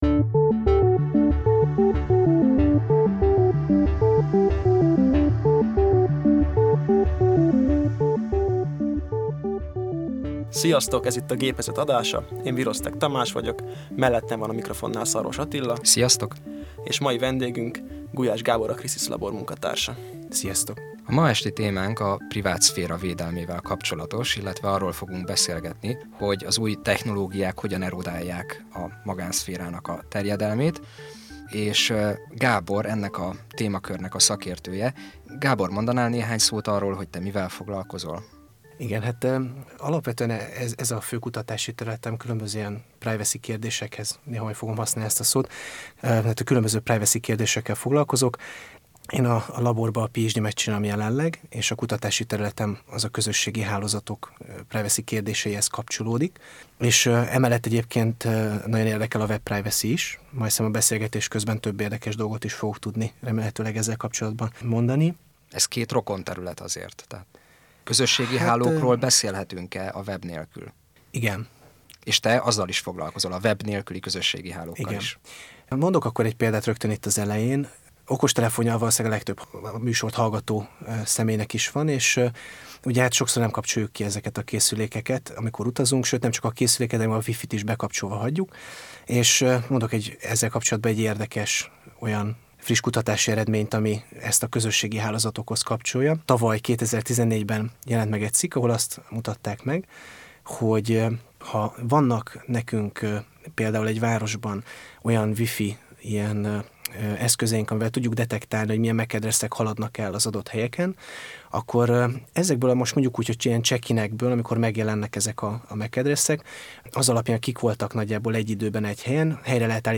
An hour long lively discussion on online privacy in the Schönerz radio, BME (10 February 2015).